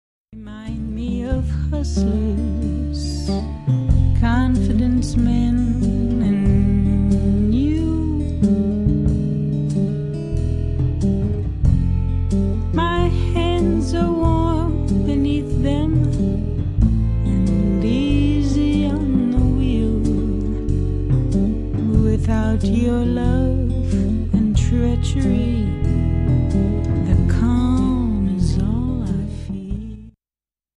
Acoustic Guitar, Vocals